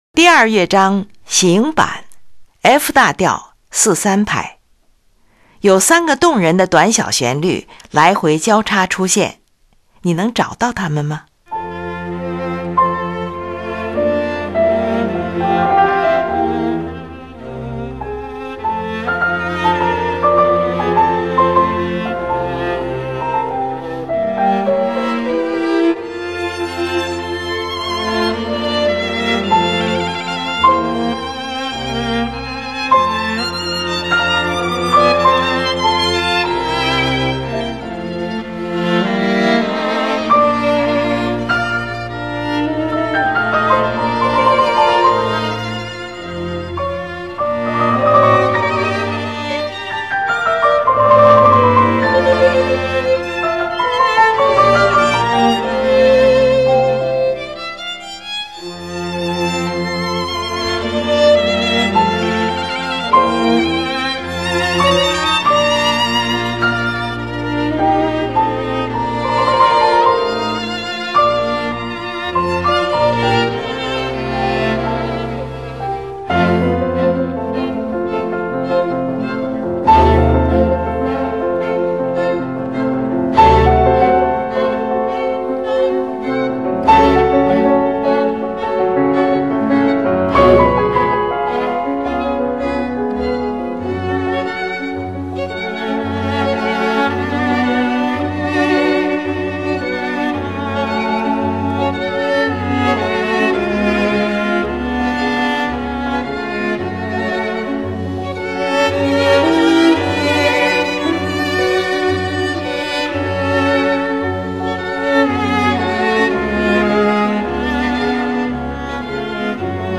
第二乐章：行板，F大调，3/4拍。有三个动人的短小旋律来回交叉出现，你能找到它们吗？